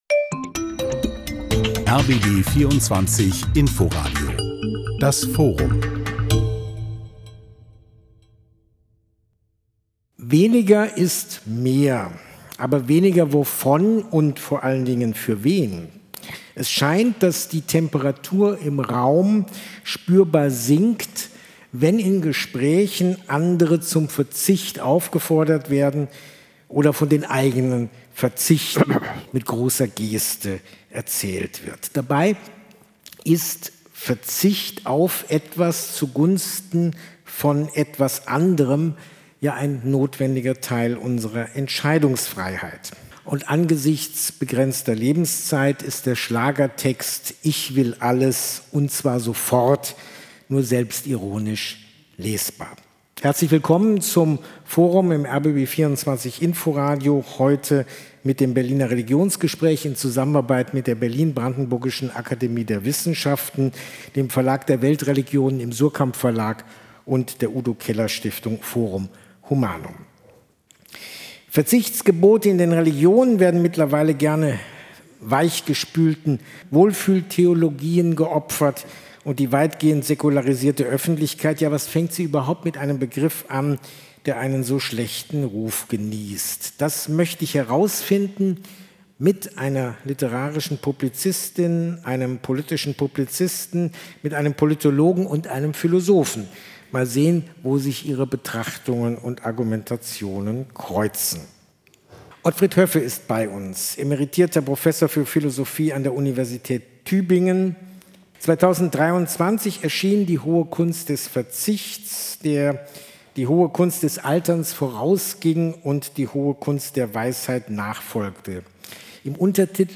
Weniger ist mehr? Aber weniger wovon – und für wen? Im Berliner Religionsgespräch in der Berlin-Brandenburgischen Akademie der Wissenschaften